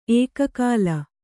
♪ ēkakāla